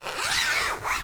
ZIPPER_Long_04_mono.wav